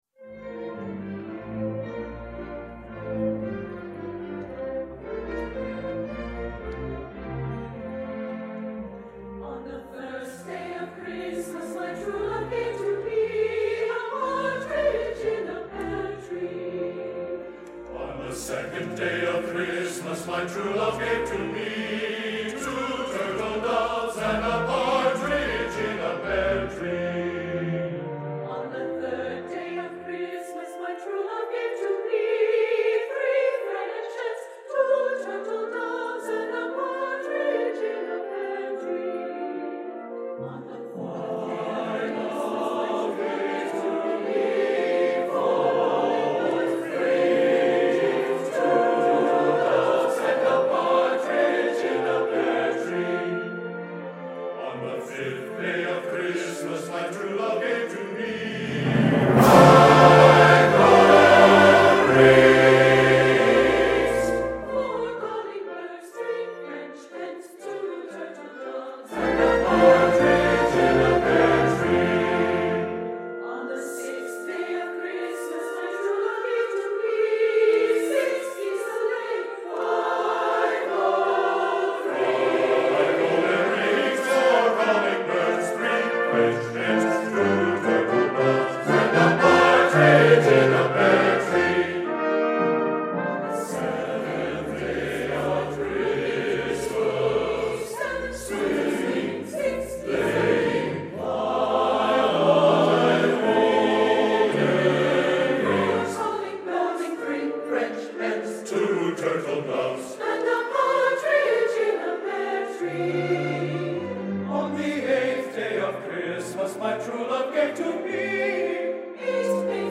Ensemble: Chamber Orchestra, SATB Chorus